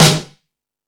Lazer Drums(18).wav